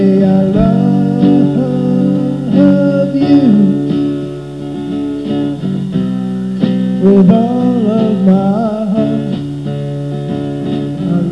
They are OK  sounding
Drums , vocals, harmonica .
Guitar, vocals, backing vocals.